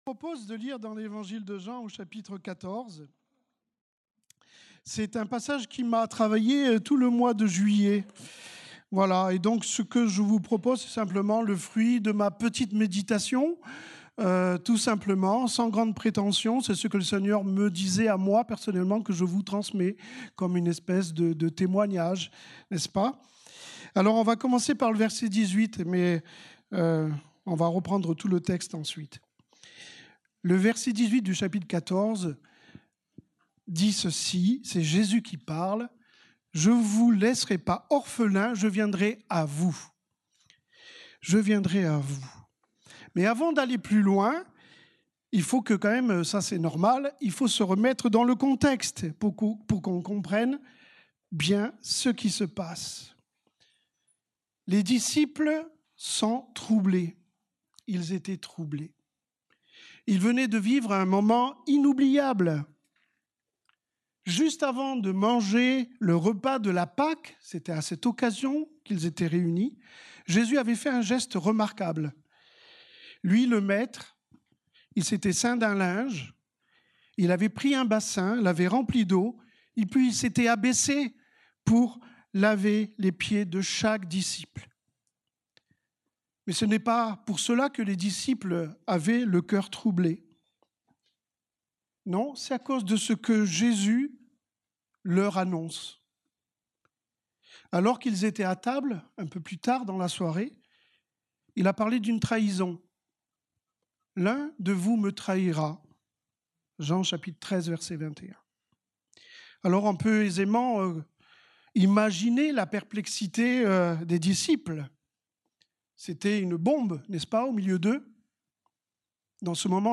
Culte du dimanche 03 août 2025, prédication